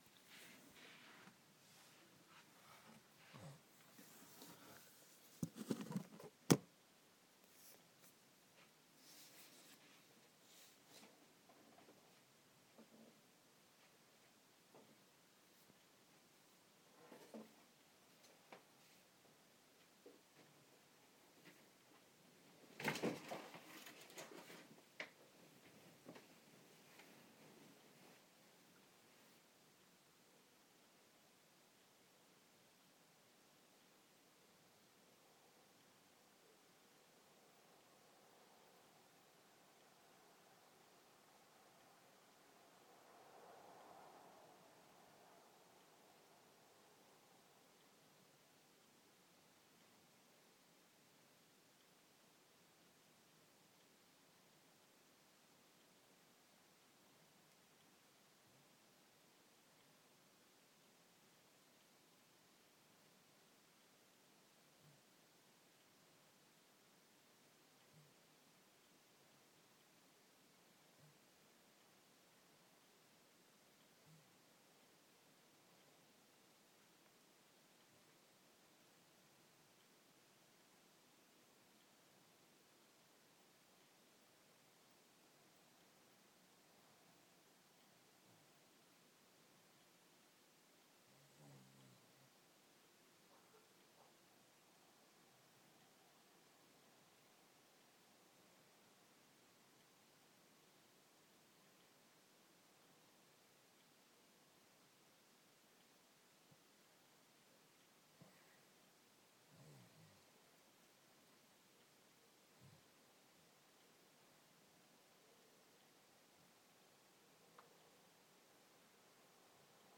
The sounds of silence! can you spot the Thunder, a catfight and a distant fox?